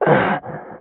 m_pain_9.ogg